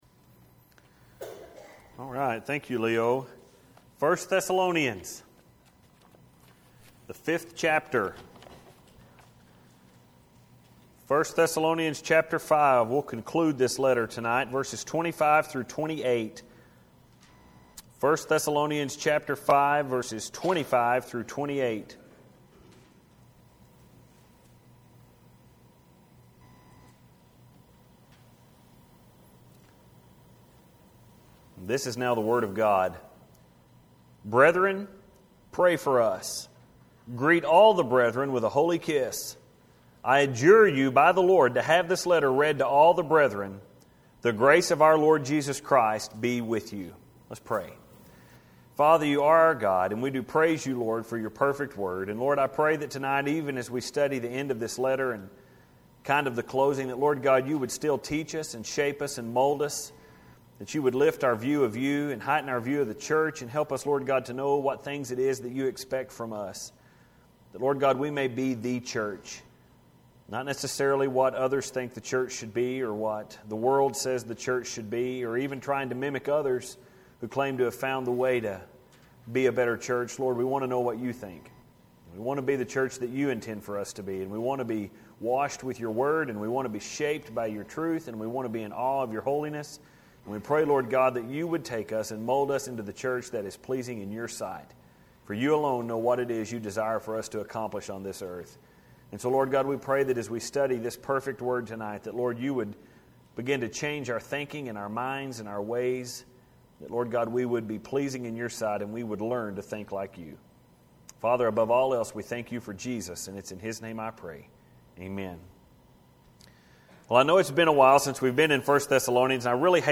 Final Encouragement 1 Thessalonians 5:25-28 May 8, 2011 (pm service)